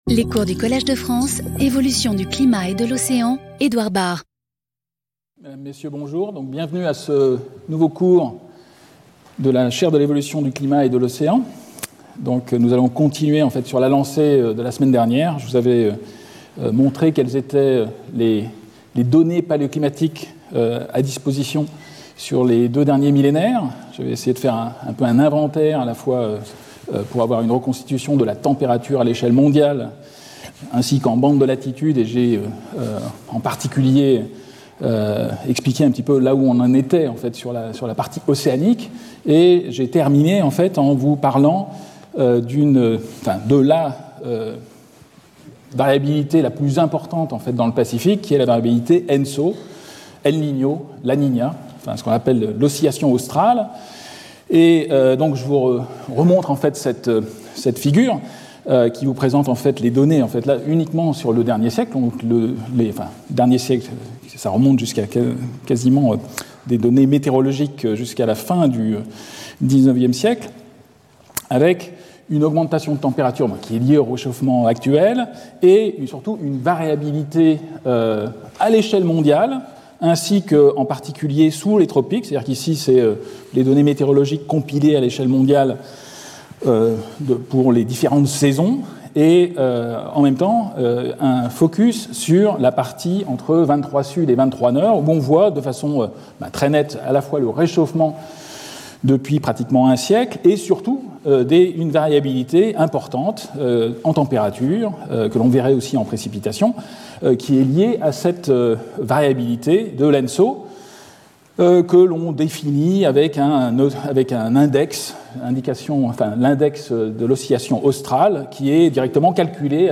Intervenant(s) Edouard Bard Professeur du Collège de France
Cours